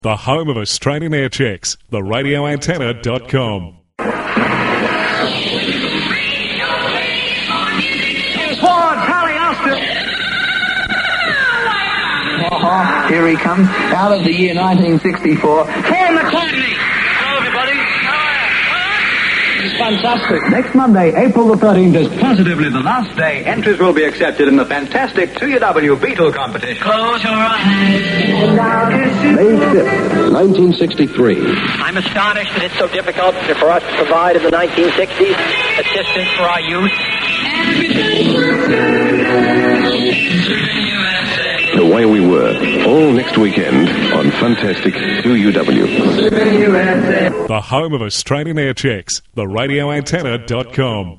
RA Aircheck – 2UW WayWeWerePromo 1974
In 1974 2UW had a promotional weekend where they featured highlights of the 60s. Here’s one of the promos for the special weekend.